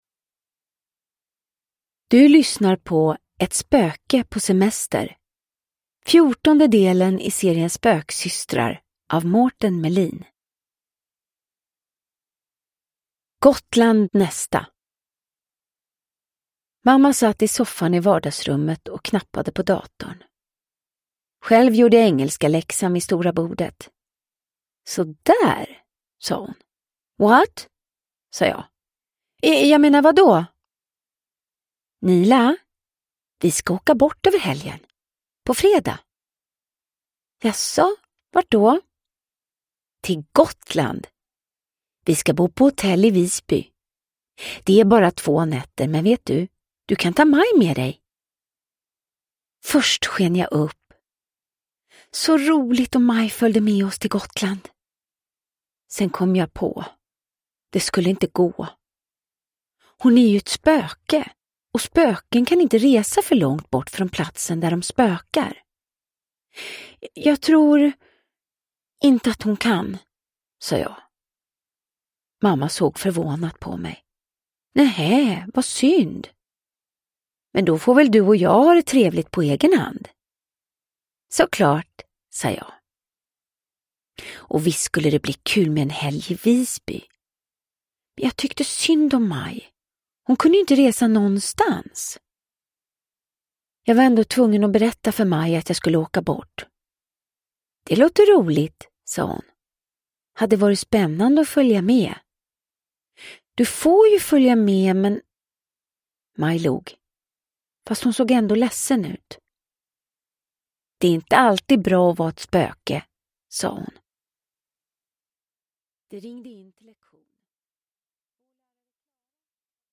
Ett spöke på semester – Ljudbok
Uppläsare: Vanna Rosenberg